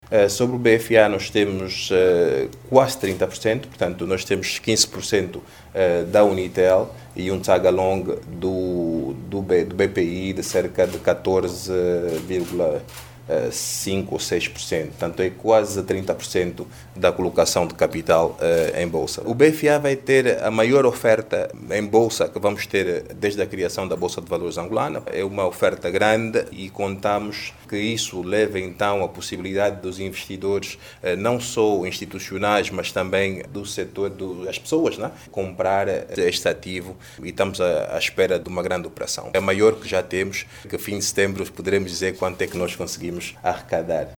Quatro grandes unidades comerciais foram privatizadas no primeiro semestre deste ano no âmbito do PROPRIV. A comissão interministerial para o programa de privatizações reuniu-se na quarta-feira(20) na Cidade Alta e concluiu que estes empreendimentos renderam até agora aos cofres do Estado mais de duzentos mil milhões de kwanzas. O presidente do Conselho de Administração, Álvaro Ferrão, fala de outras empresas que vão entrar em funcionamento este ano.